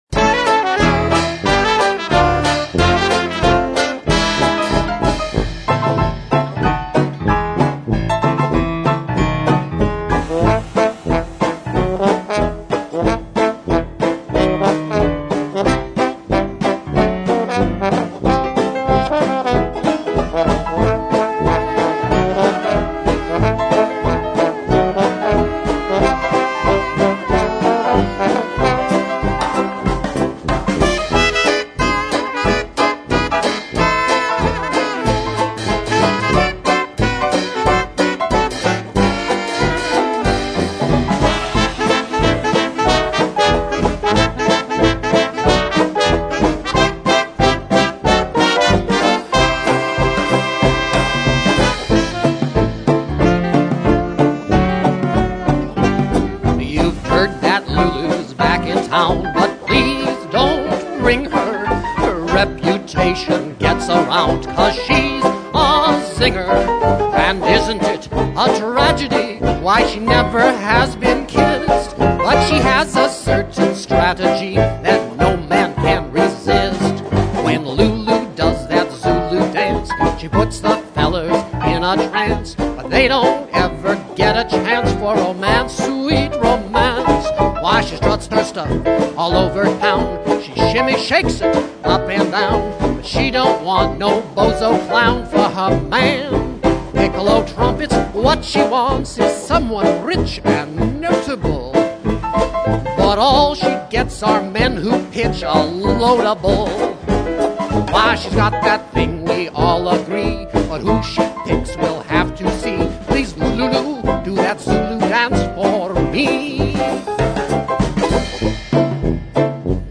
1920s Syncopated Jazz and 1930s-Big Band Swing styles